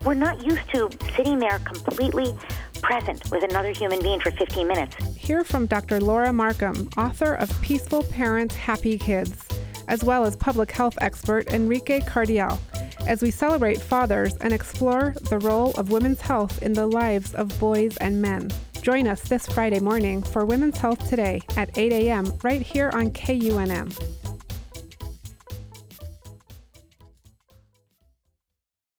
KUNM promo 6.14.13
KUNM-promo.wav